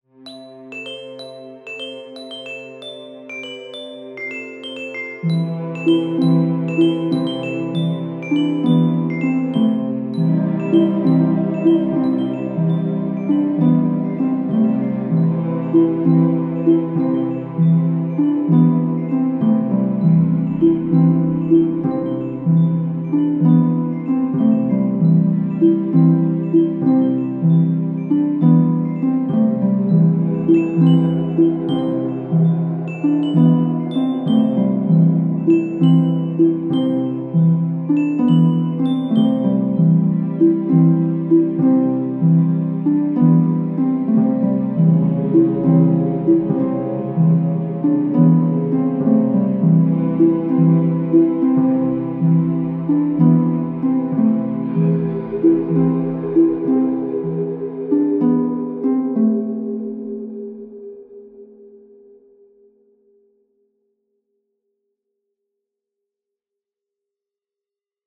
Die Musik sollte eine fantastische und mysteriöse Stimmung haben.
Somit hatten wir ein digitales Orchester schnell und einfach zur Hand.
Zum Ausprobieren wurden ein paar musikalische Konzeptionen erstellt.